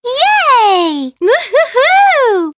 One of Princess Peach's voice clips in Mario Kart 7